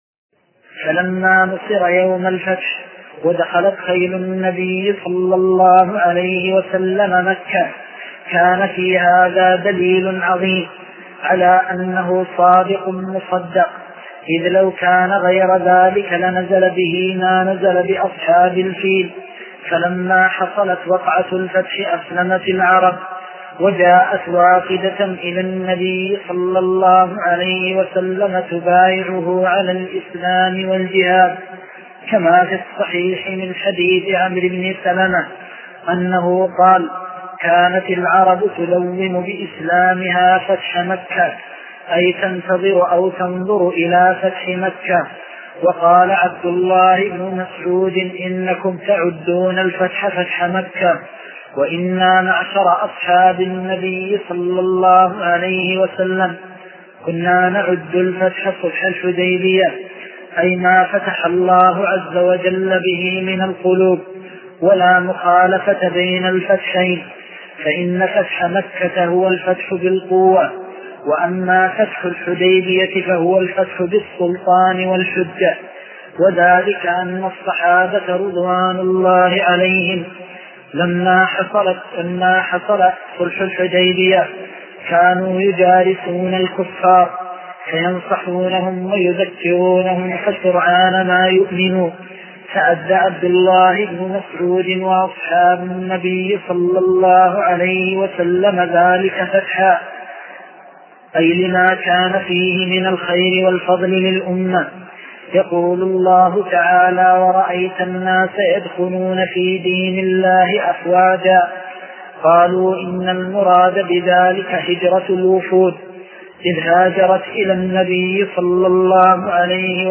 حديث عمدة الأحكام شرح جدة القديم